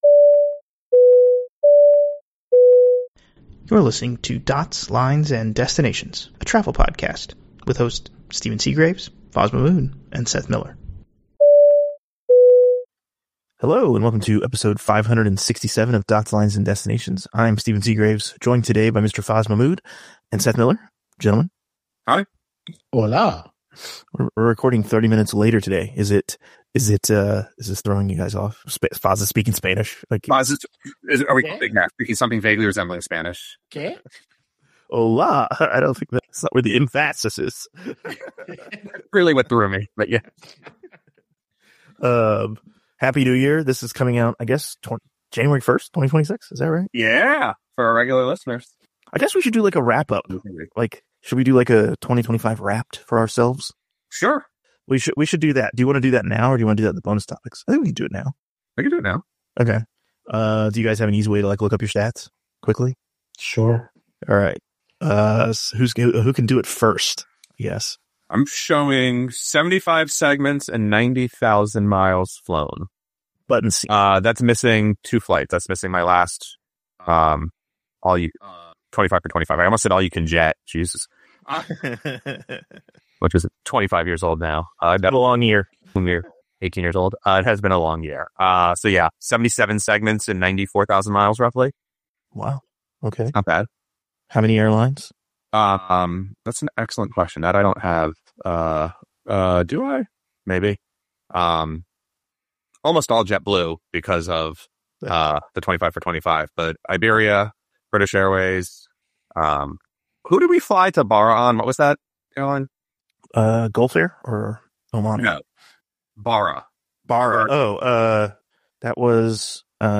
Three guys who will do almost anything for another travel adventure.